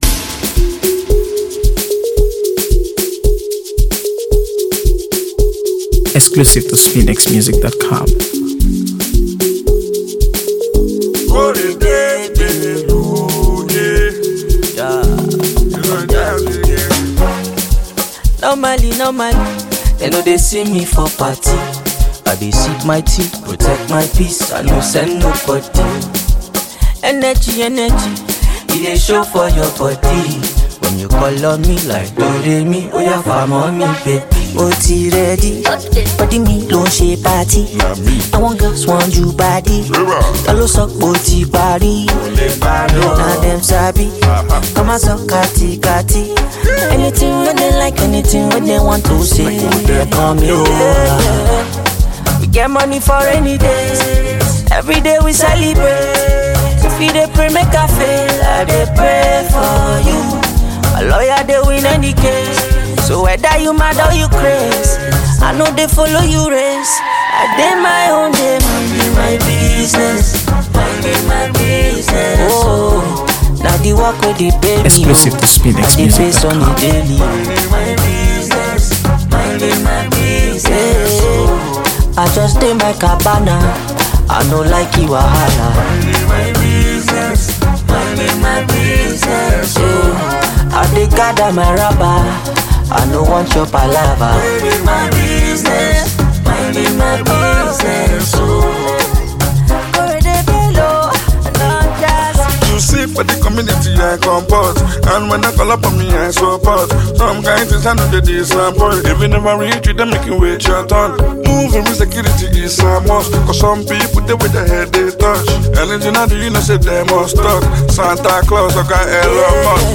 AfroBeats | AfroBeats songs
the talented Nigerian singer-songwriter and performer
soulful melodies